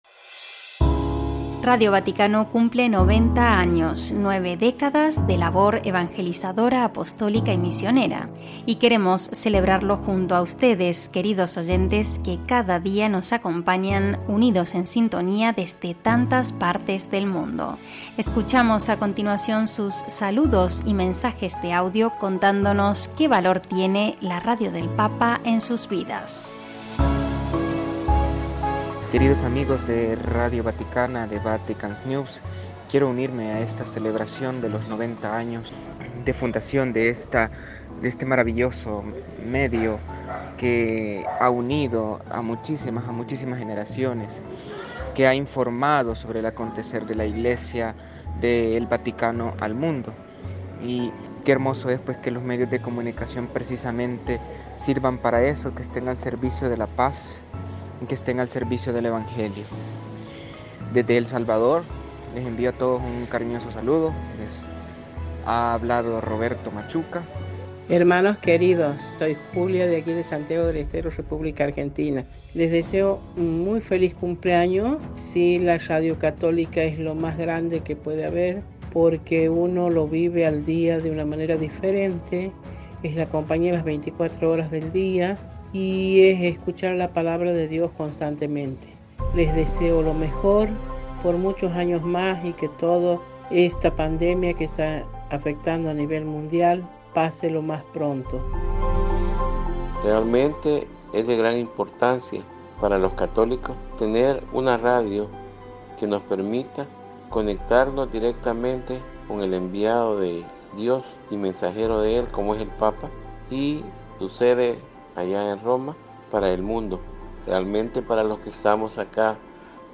Para celebrar esta importante fecha, compartimos los mensajes de audios enviados por nuestros oyentes con sus saludos y oraciones: nuestra fiel audiencia, que cada día nos acompaña a través de las ondas y Redes Sociales, nos cuenta qué valor representa la Radio del Papa en su vida espiritual.